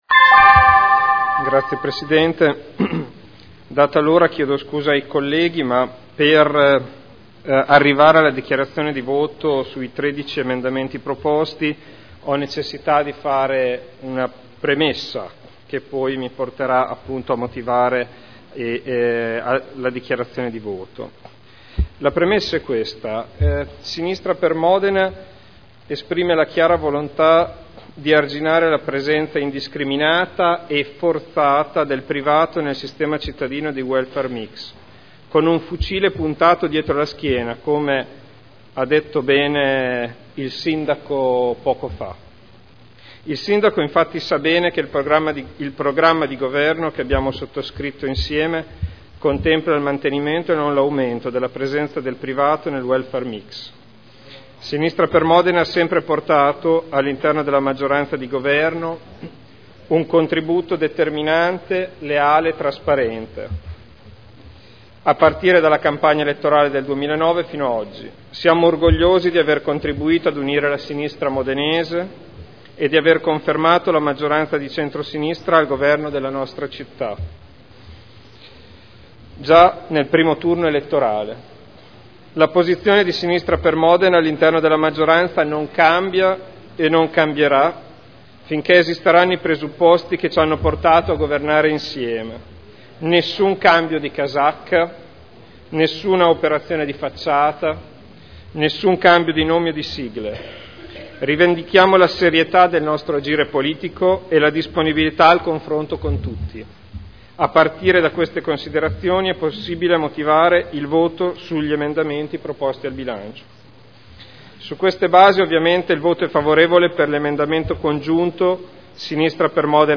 Federico Ricci — Sito Audio Consiglio Comunale
Seduta del 28/03/2011. Dichiarazioni di voto su emendamenti.